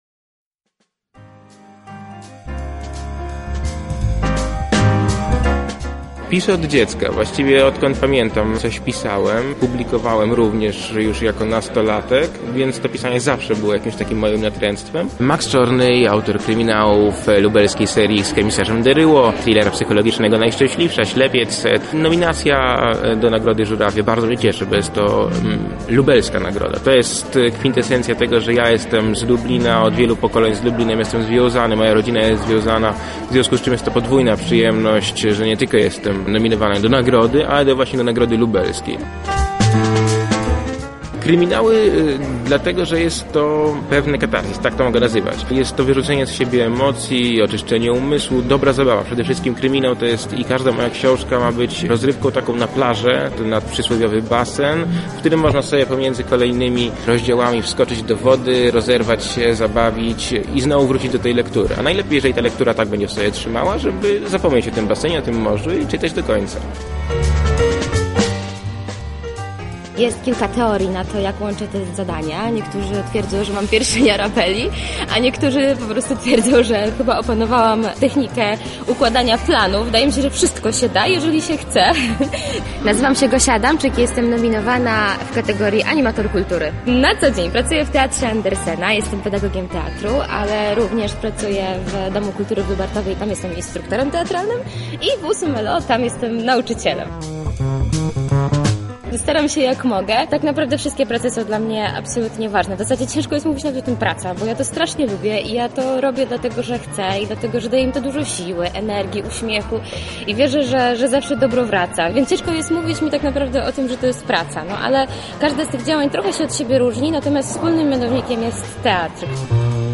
Rozmowy z nominowanymi